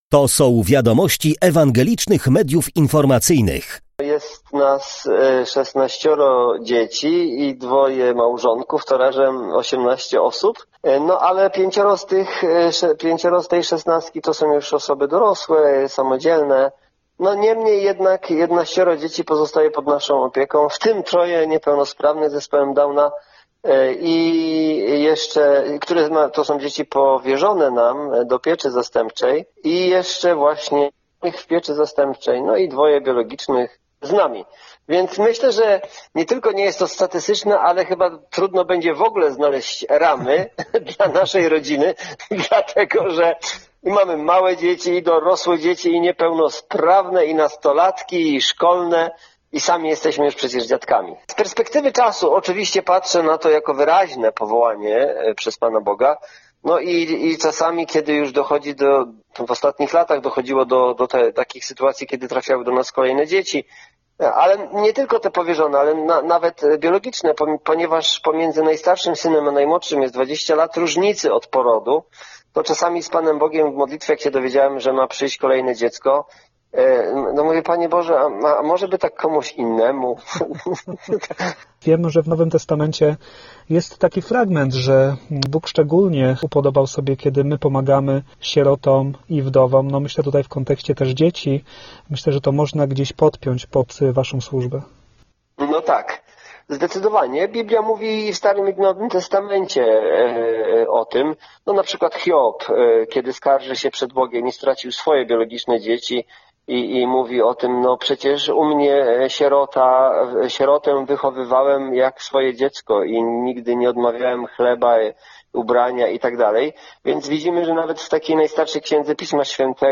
duda-cala-rozmowa.mp3